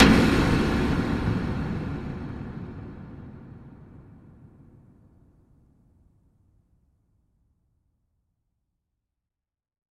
Jumpscare_11.wav